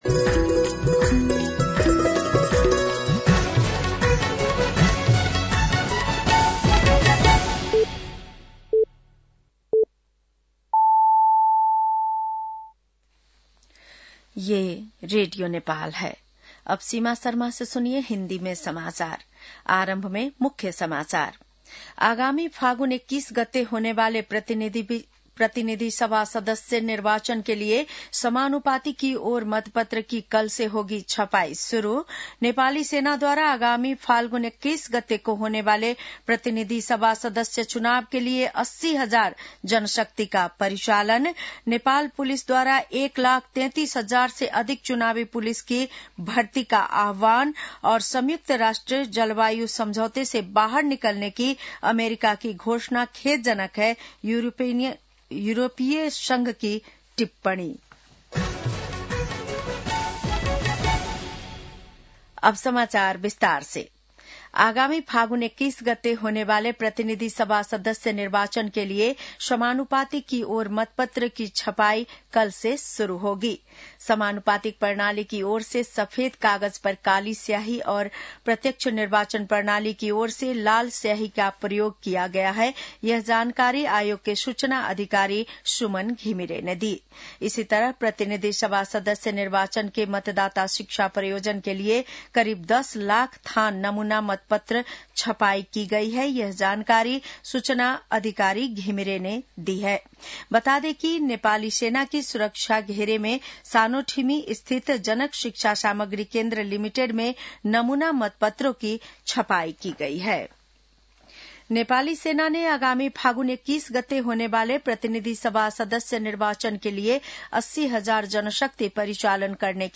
बेलुकी १० बजेको हिन्दी समाचार : २४ पुष , २०८२
10-PM-Hindi-NEWS-9-24.mp3